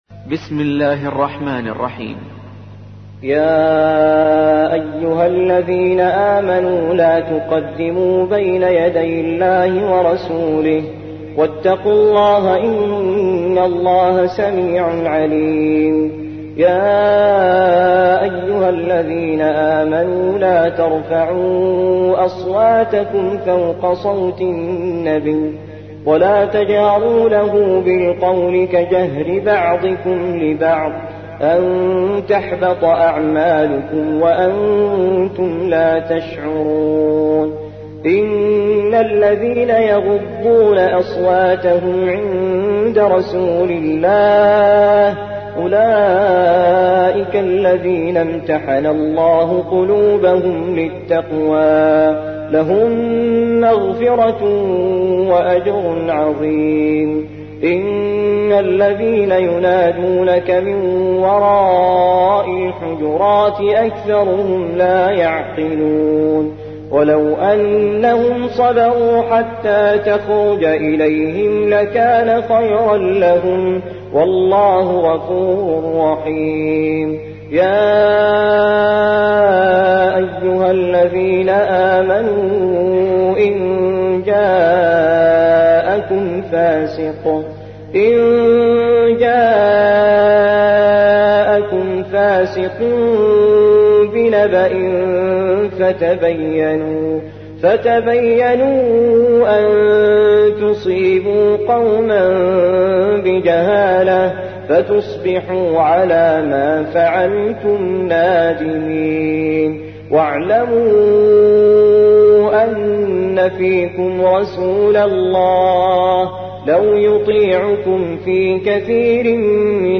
49. سورة الحجرات / القارئ